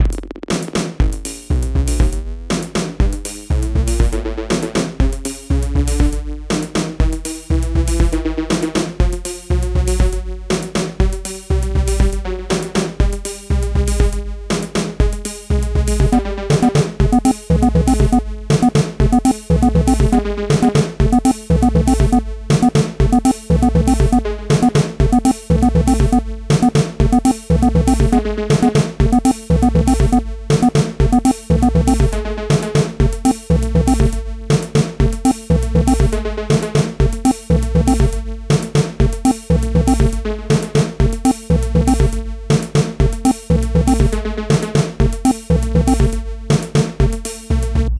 Bucle de Electro
Música electrónica
melodía repetitivo sintetizador